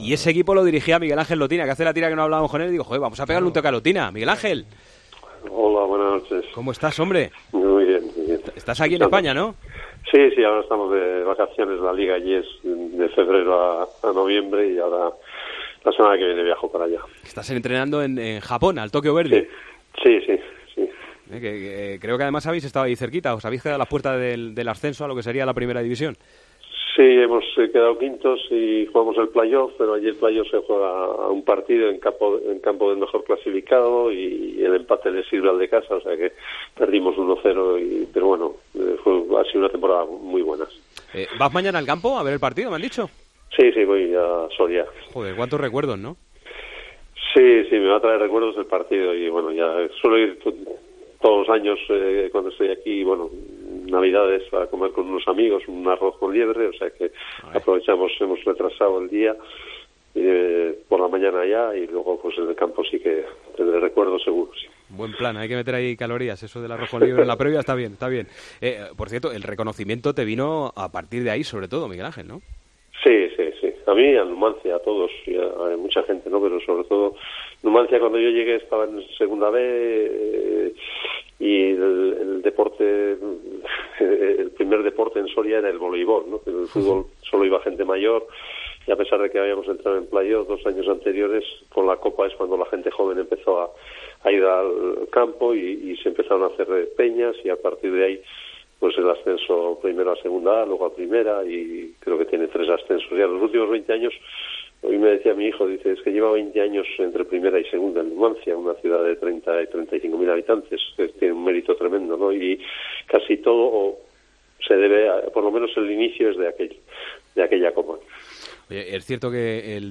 Entrevistas en El Partidazo de COPE